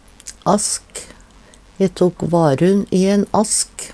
ask - Numedalsmål (en-US)